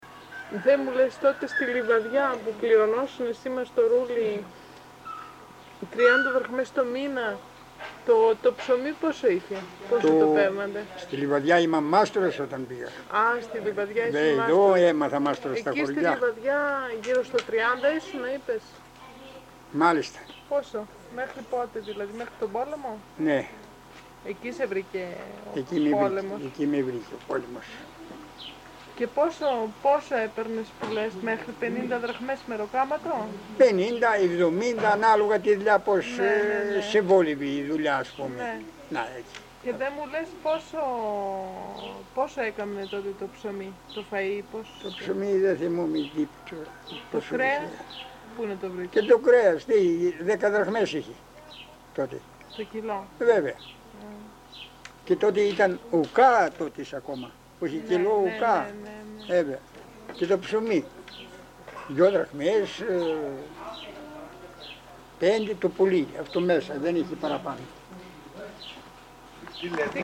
Συνέντευξη με χτίστη από τα Μαστοροχώρια. (EL)